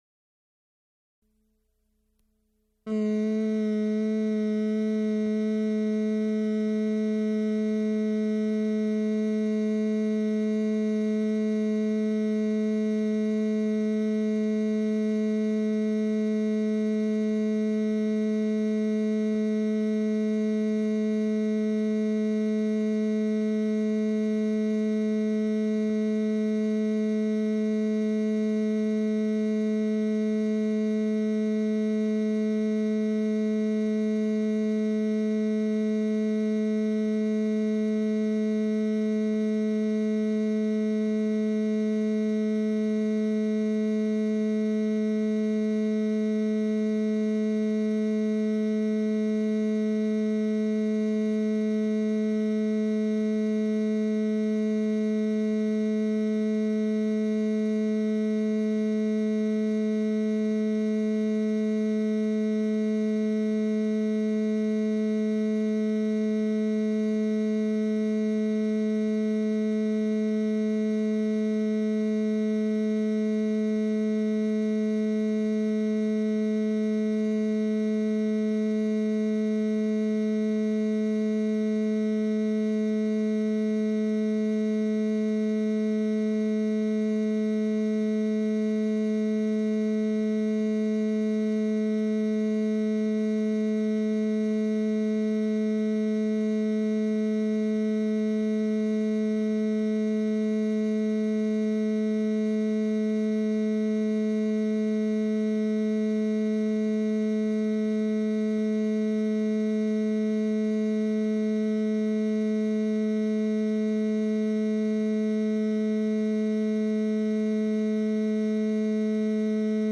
A Concert of computer and electronic music | Digital Pitt
Recorded July 31, 1980, Frick Fine Arts Auditorium, University of Pittsburgh.
Extent 2 audiotape reels : analog, half track, 7 1/2 ips ; 12 in.
Computer music Electronic music